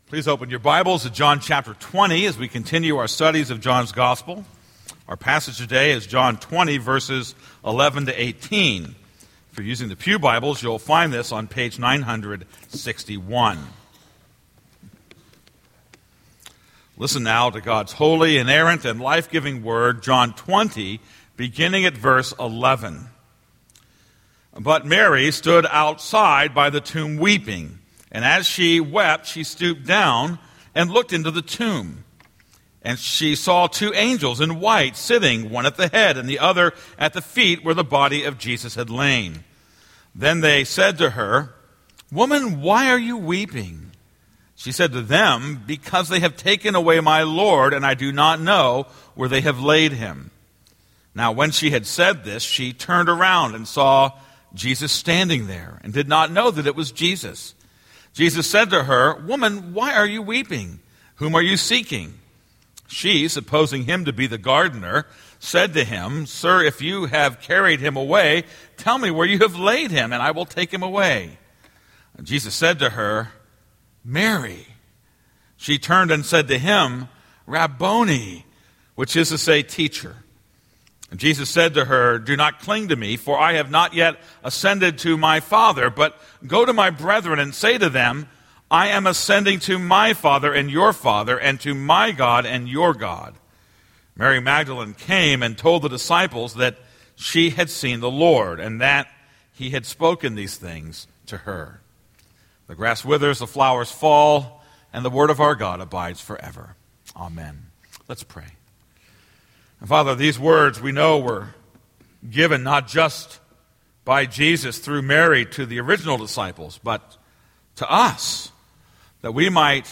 This is a sermon on John 20:11-18.